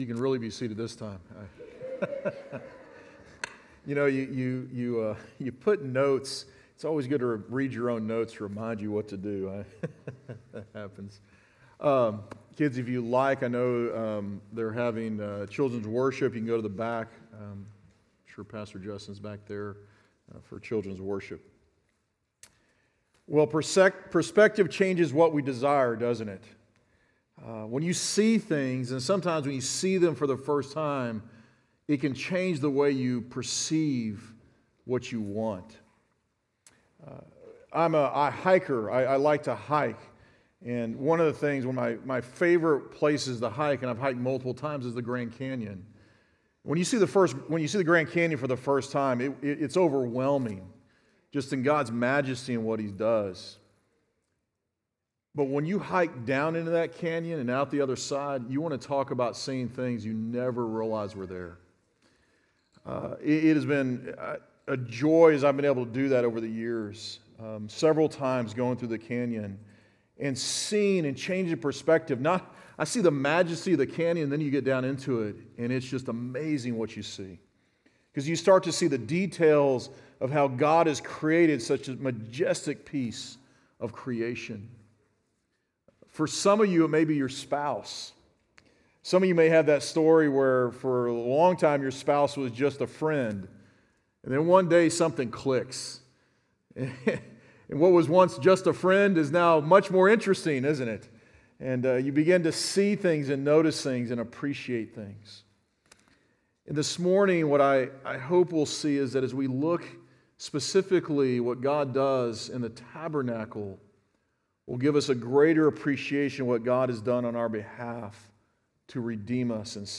Sermon Audio Only